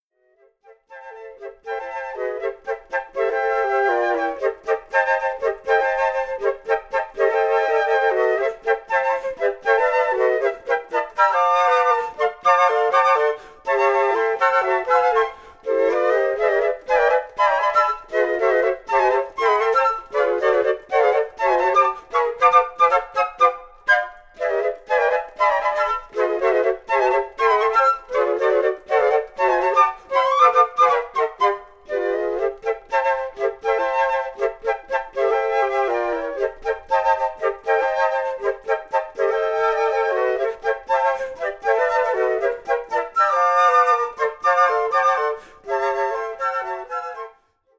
Pour quatuor de flûtes